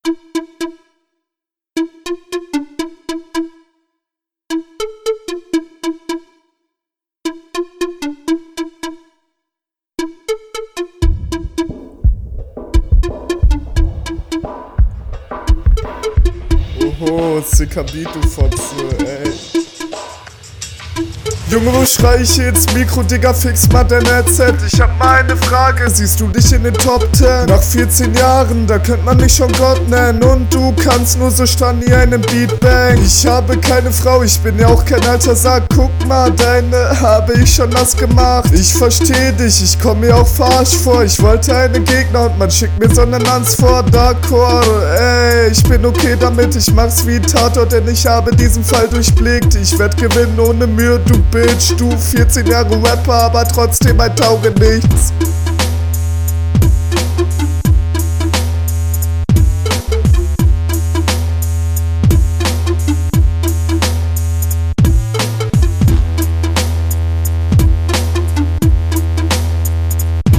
Verstehe manchmal nicht so ganz was du sagst, du versuchst auch bissle mit der Stimme …
Sound ist auch okay, finde ich aber bei deinem Gegner besser.